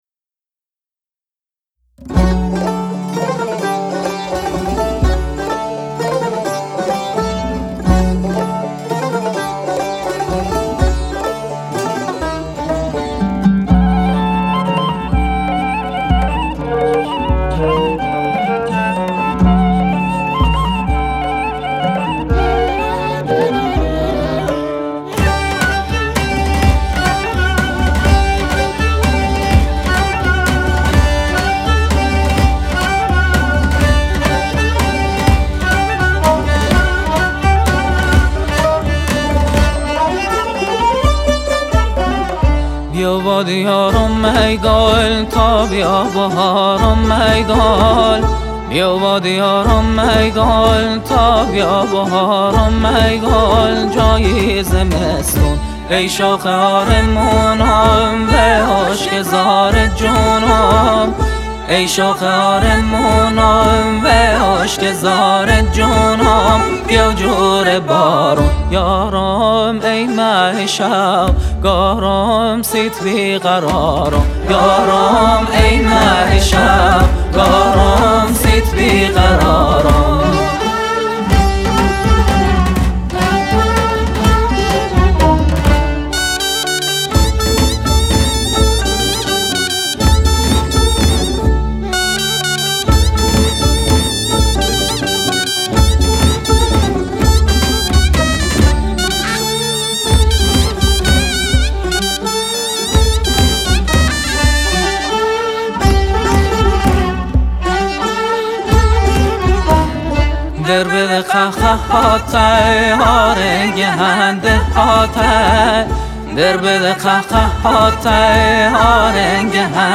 بختیاری